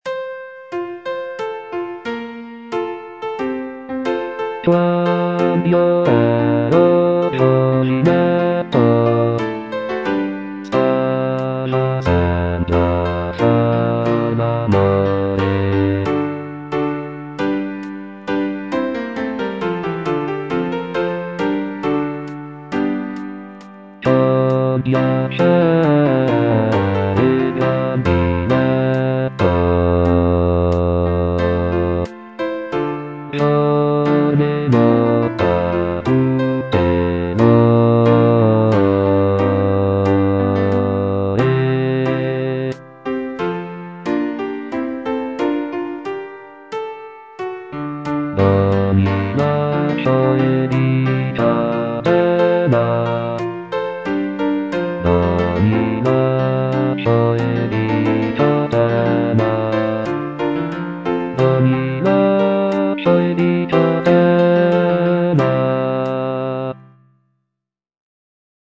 G. GABRIELI - QUAND'IO ERO GIOVINETTO Quand'io ero giovinetto - Bass (Voice with metronome) Ads stop: auto-stop